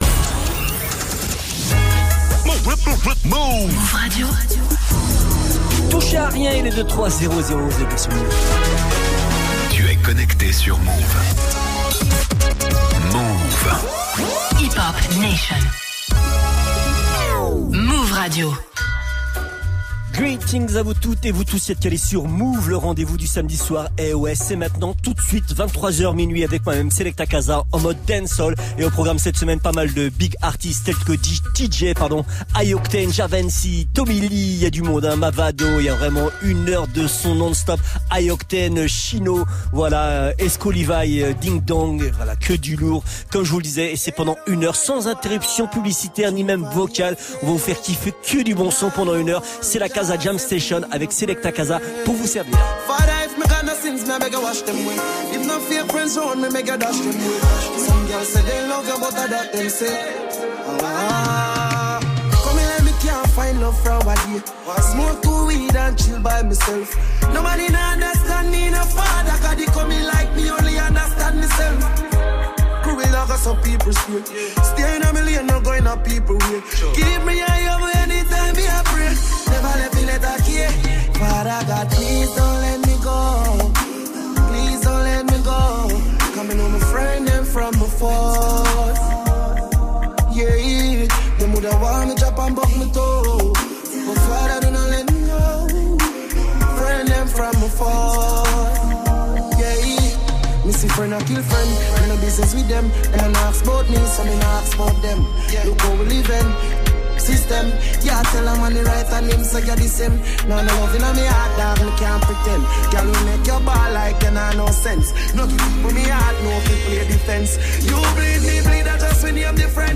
sélection pointue des dernières tendances “Made In Jamaica”
très dansante